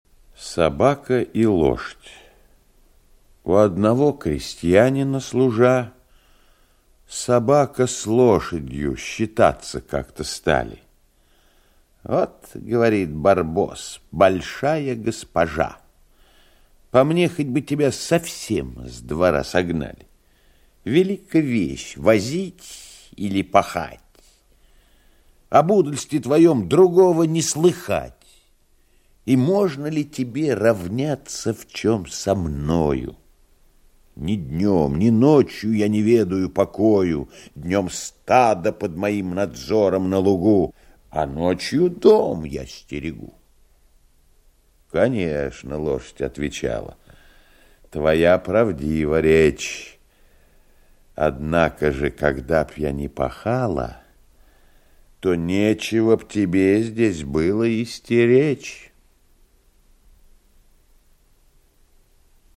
Собака и Лошадь - аудио басня Крылова - слушать онлайн